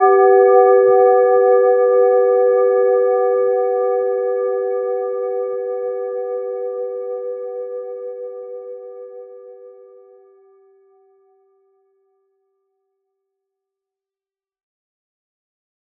Gentle-Metallic-2-B4-mf.wav